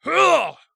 ZS发力7.wav 0:00.00 0:00.76 ZS发力7.wav WAV · 66 KB · 單聲道 (1ch) 下载文件 本站所有音效均采用 CC0 授权 ，可免费用于商业与个人项目，无需署名。
人声采集素材/男3战士型/ZS发力7.wav